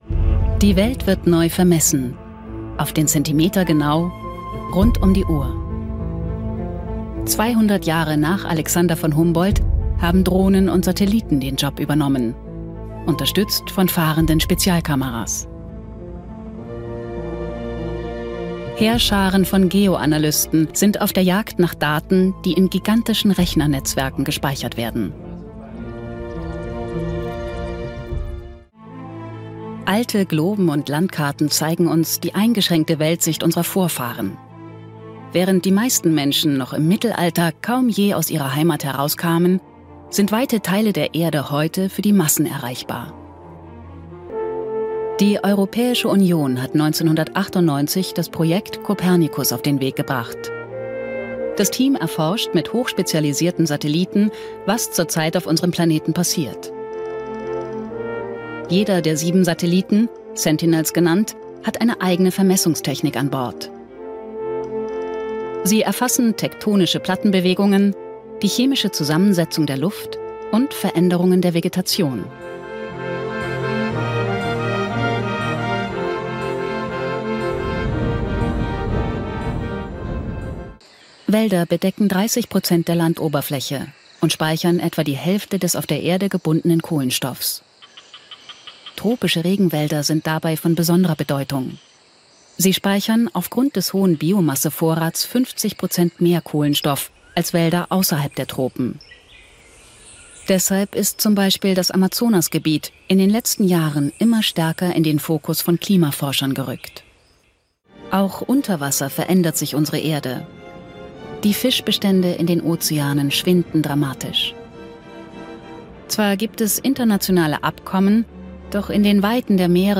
Dokumentation - Erzählstimme
ernst, sachlich